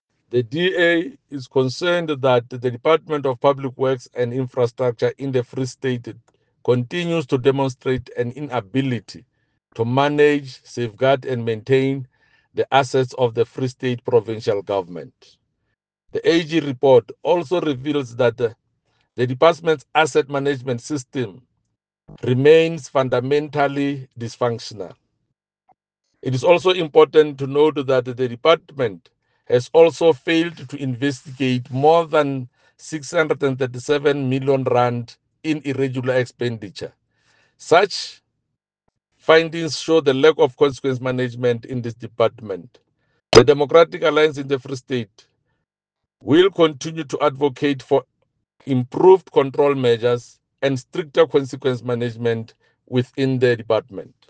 Sesotho soundbites by Jafta Mokoena MPL